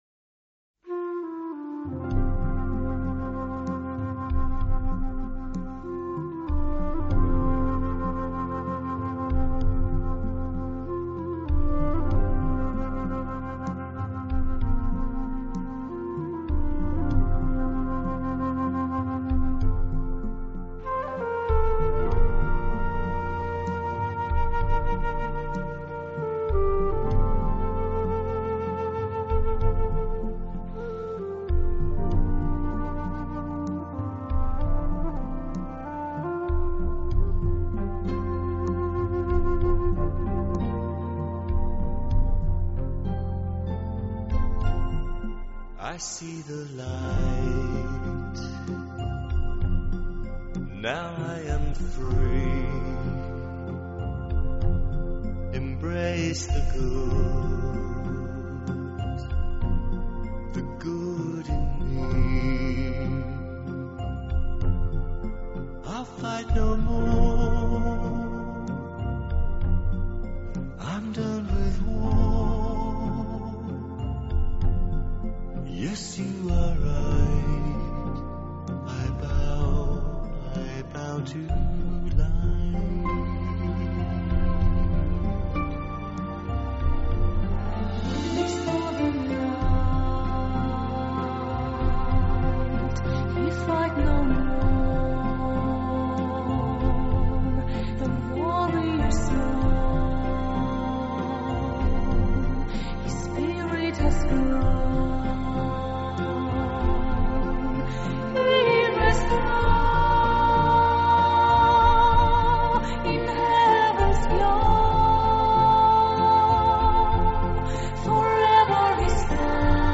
专辑语言：纯音乐
淌的音乐带着落寂找寻失去的天空，笛音悠长，绵绵不绝于耳，长笛以一份低沉把思绪抛撒。
此专辑完全融合浸润了圣歌的和声，而那女高音的嘹亮激亮你飞翔的欲望！
娴熟的长笛吹奏，弗拉门科的吉他，澳洲土著人的迪吉里杜管，打击乐器、小号、竖琴和双簧